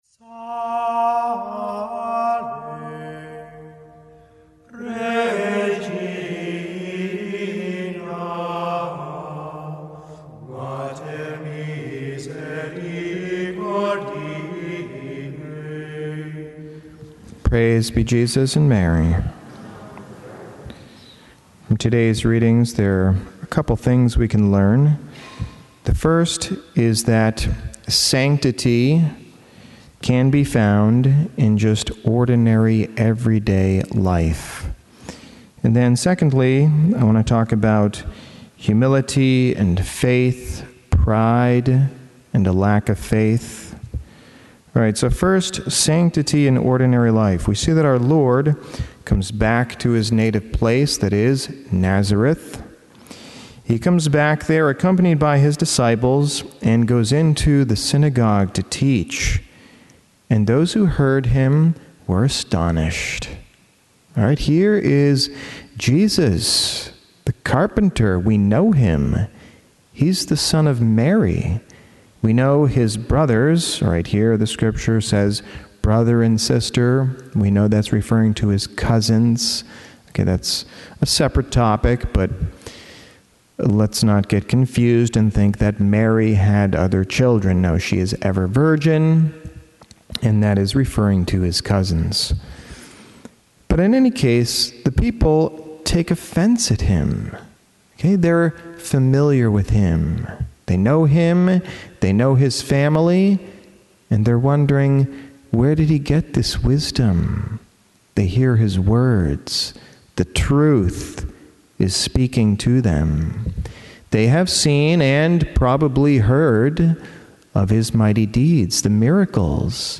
Mass: 14th Sunday in Ordinary Time - Sunday - Form: OF Readings: 1st: eze 2:2-5 Resp: psa 123:1-2, 2, 3-4 2nd: 2co 12:7-10 Gsp: mar 6:1-6 Audio (MP3) +++